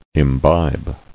[im·bibe]